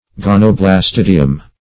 Search Result for " gonoblastidium" : The Collaborative International Dictionary of English v.0.48: Gonoblastidium \Gon`o*blas*tid"i*um\, n.; pl.